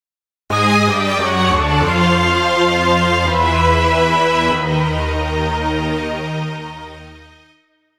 ゲーム制作用
ゲームオーバー